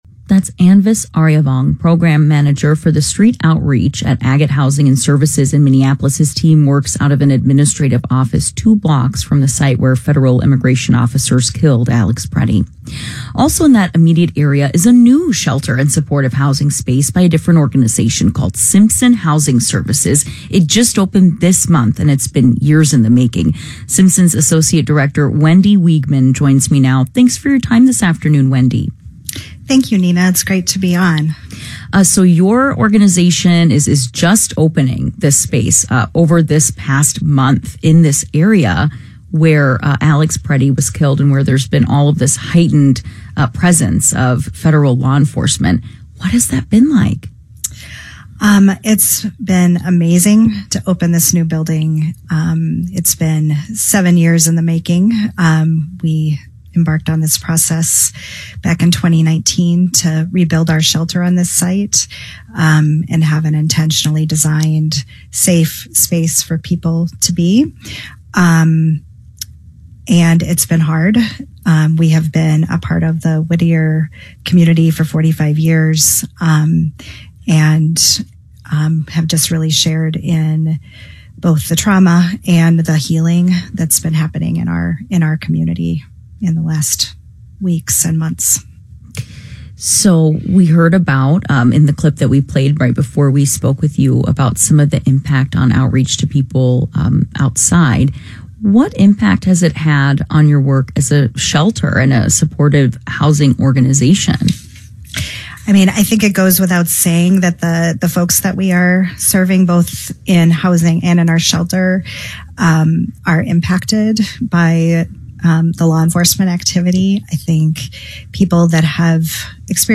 Simpson Community Shelter & Apartments MPR Interview Audio - Simpson Housing Services